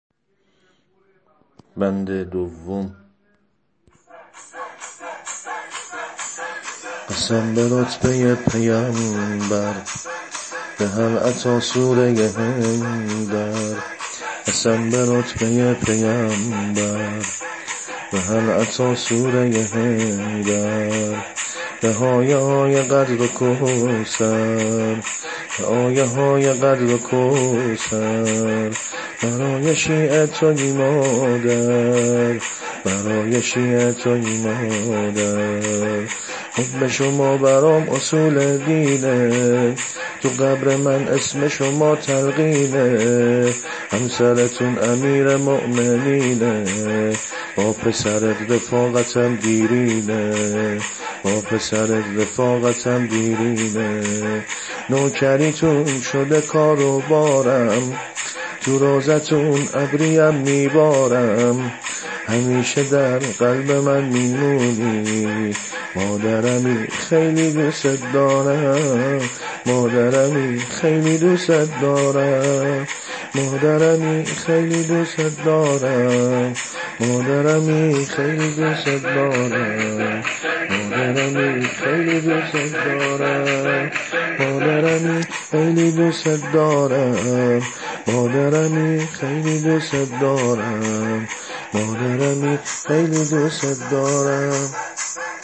سبک شور حضرت فاطمه زهرا سلام الله علیها -(قسم به آیات الهی)